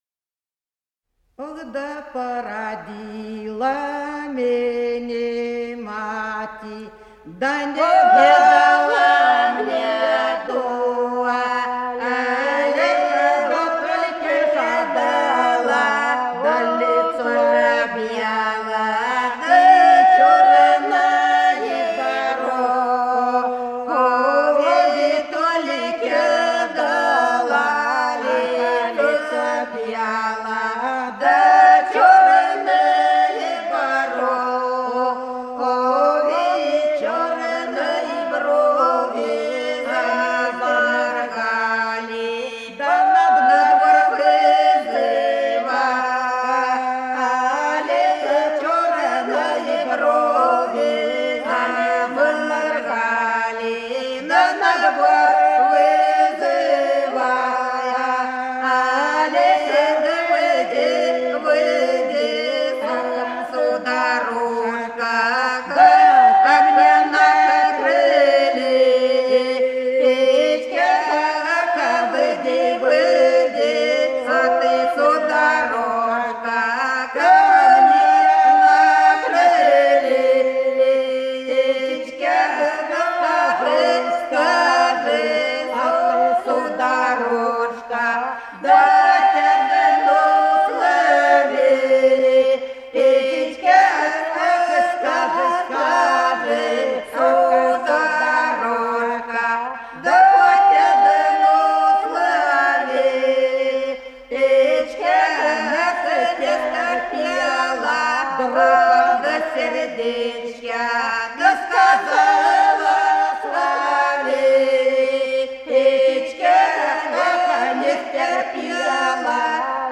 Голоса уходящего века (село Подсереднее) Породила мене мати